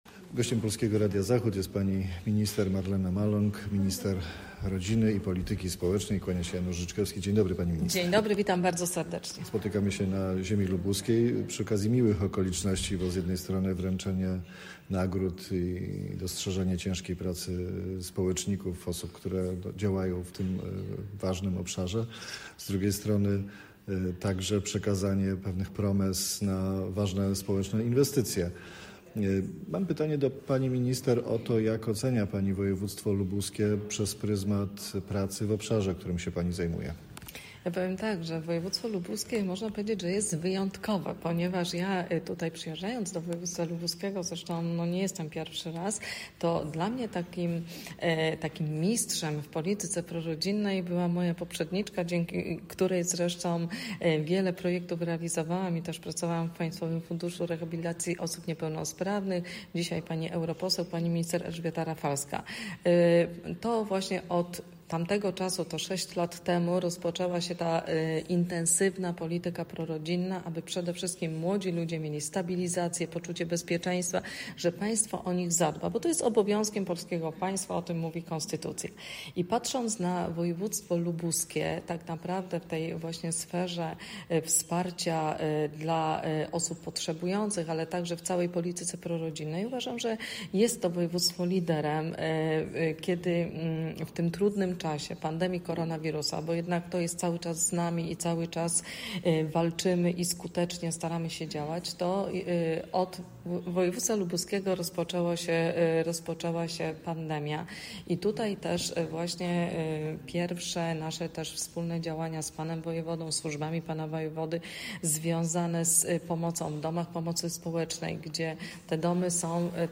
Z Minister Rodziny i Polityki Społecznej rozmawia